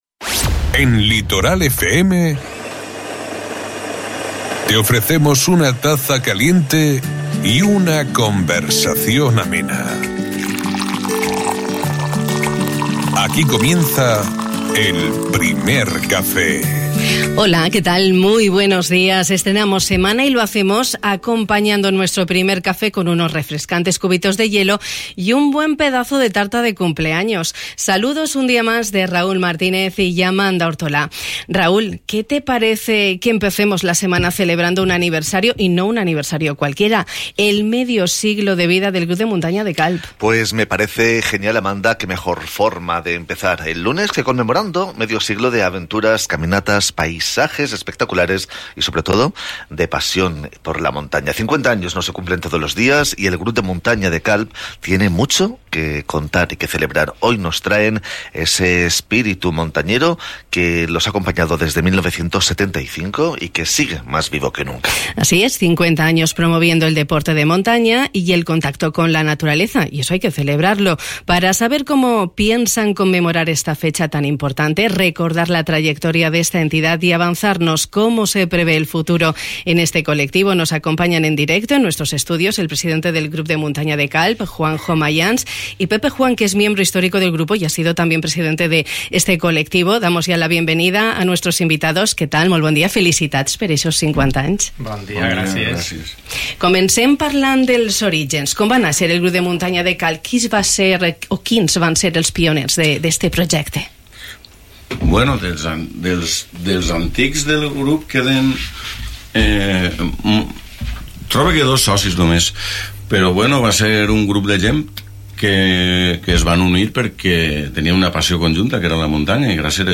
Esta mañana hemos dedicado tiempo de radio a la celebración del medio siglo de vida del Grup de Muntanya de Calp.